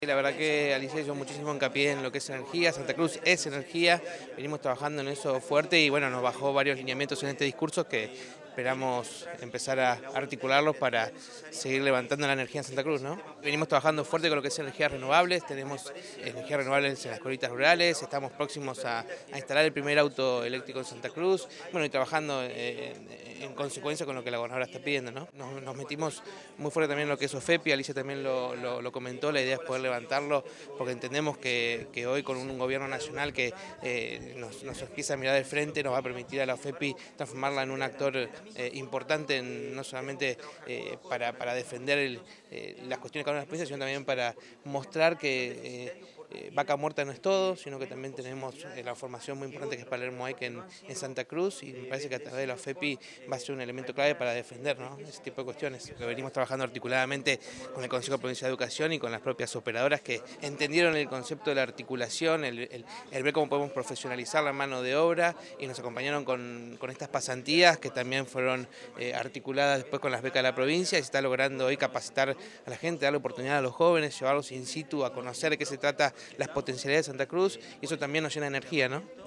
Así lo manifestó, el Presidente del Instituto de Energía de la Provincia de Santa Cruz, Matías Kalmus, en este inicio del período ordinario de sesiones concretado el día de hoy en la Cámara de Diputados de Río Gallegos.